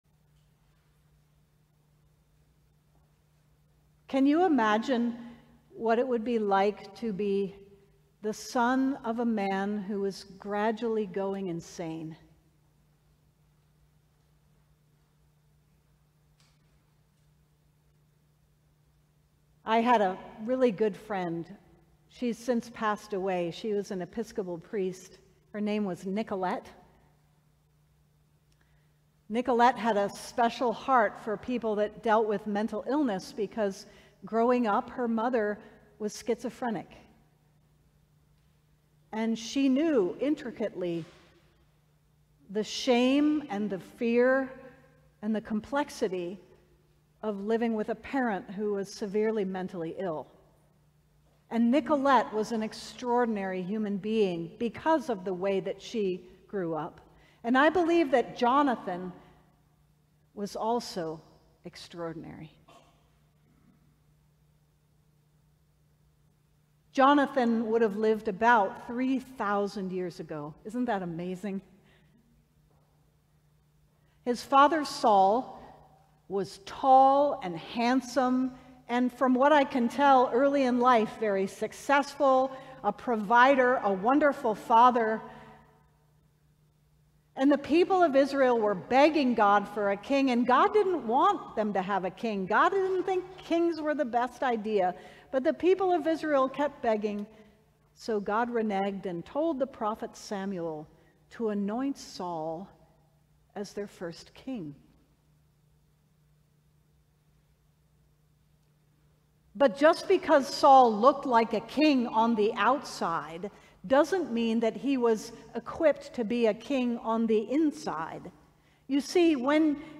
The Fifth Sunday after Pentecost We invite you to join us in worship at St. John’s Cathedral, whether in person or online, on Sunday mornings.
Sermons from St. John's Cathedral